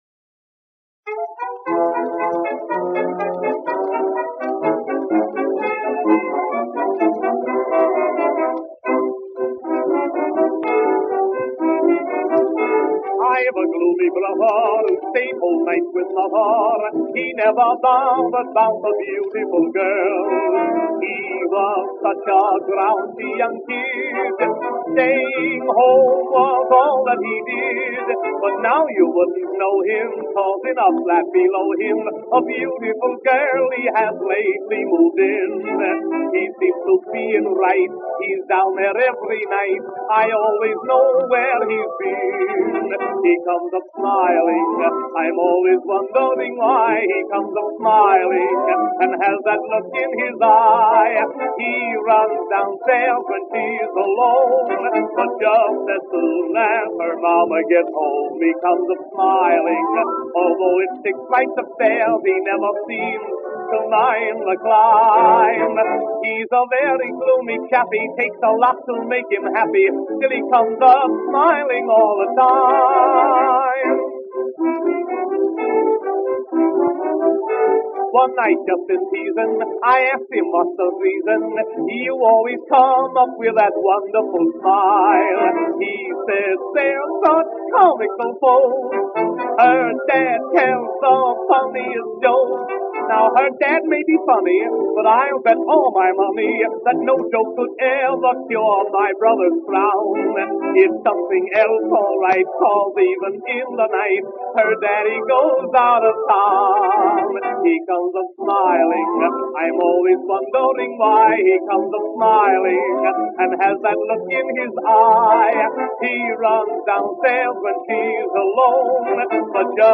78rpm